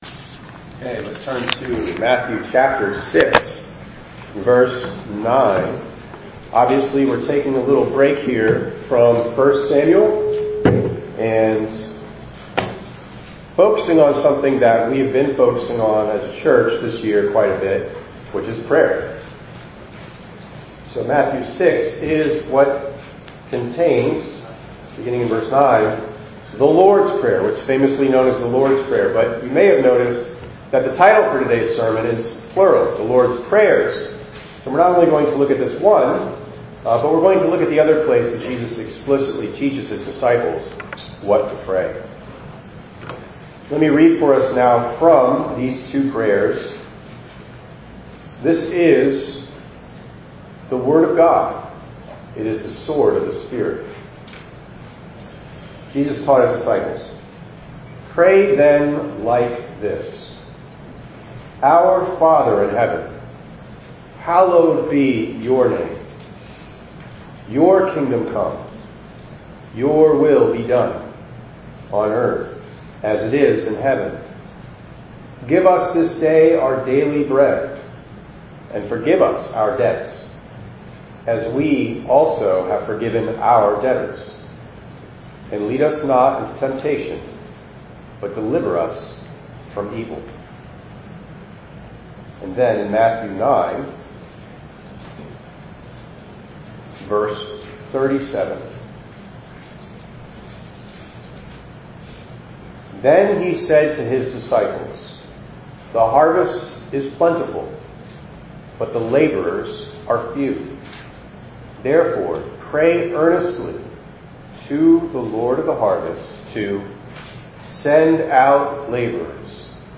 7_13_25_ENG_Sermon.mp3